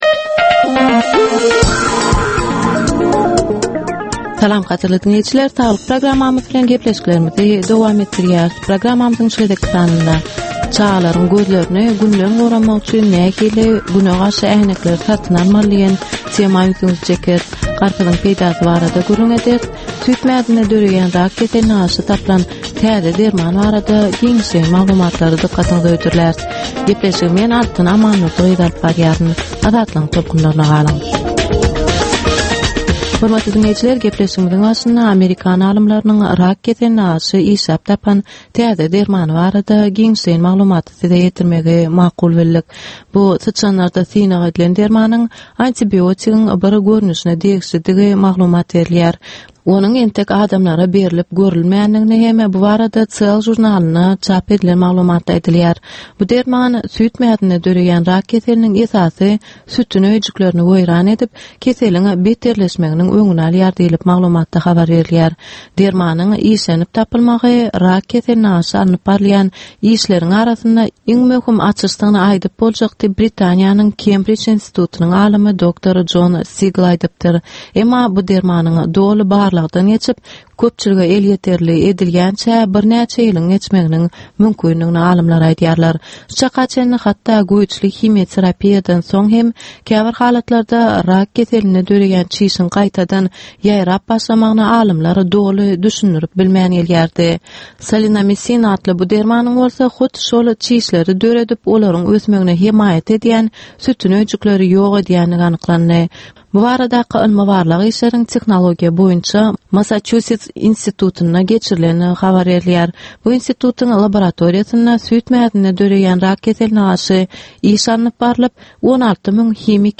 Ynsan saglygyny gorap saklamak bilen baglanysykly maglumatlar, täzelikler, wakalar, meseleler, problemalar we çözgütler barada 10 minutlyk ýörite geplesik.